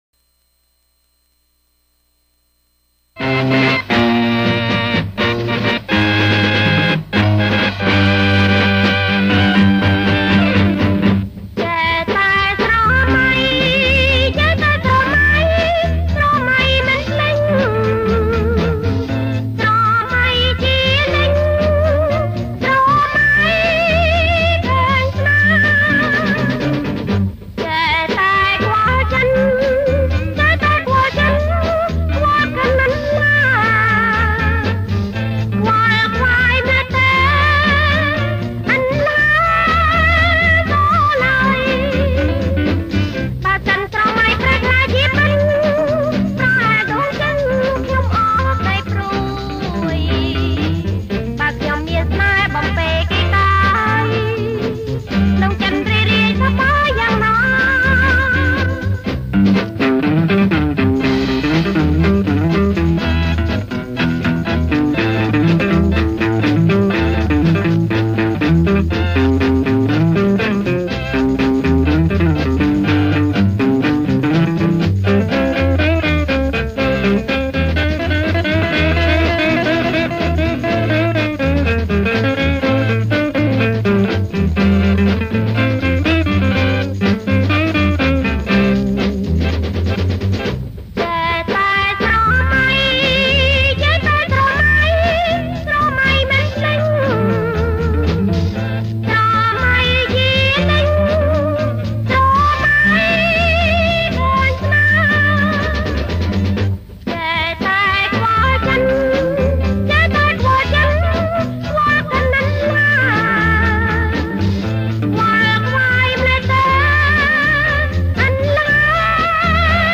ប្រគំជាចង្វាក់ Jerk